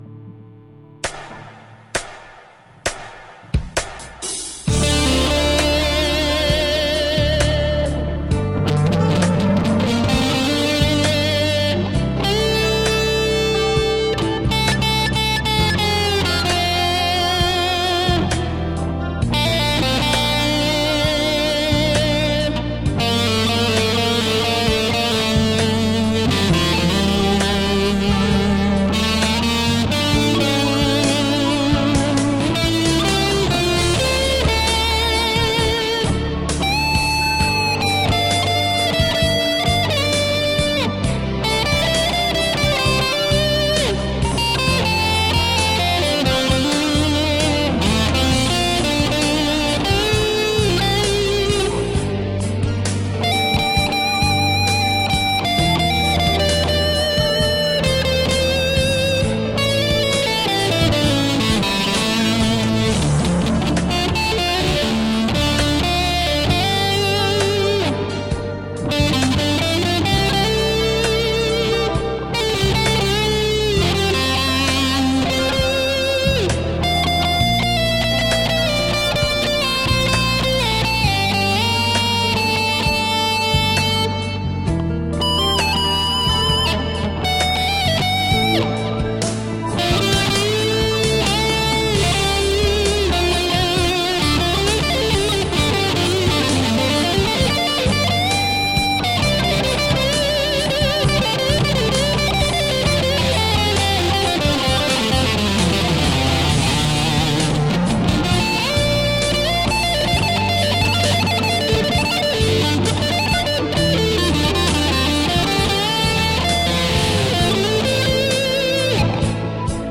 Composição: Improviso.